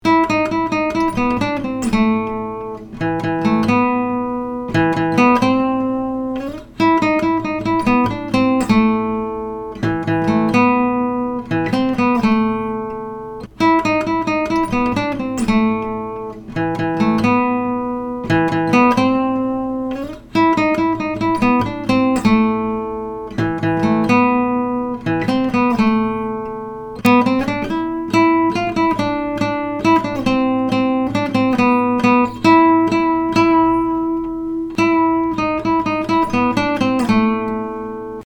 Play on the (G) string